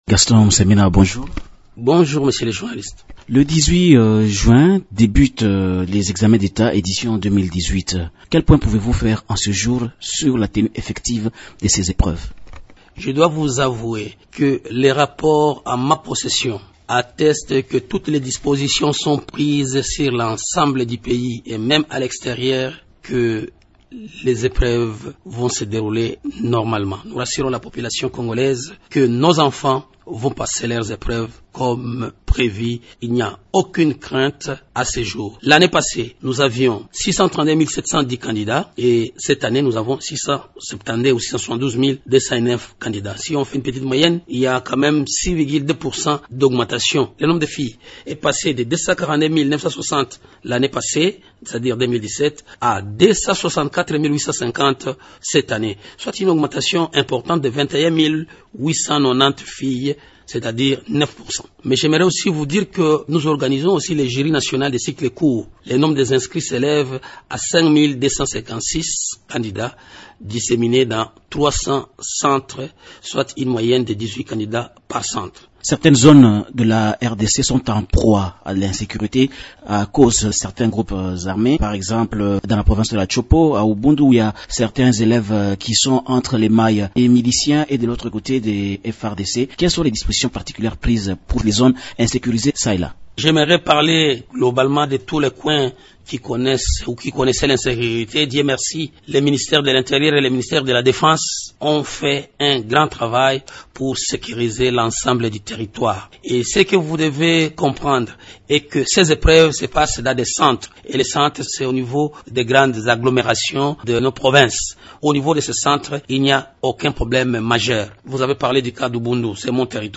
Dans une interview exclusive accordée à Radio Okapi, le ministre de l’EPSP rappelle que tout élève régulièrement inscrit doit passer l’examen sans être inquiété pour quoi que ce soit, y compris les frais.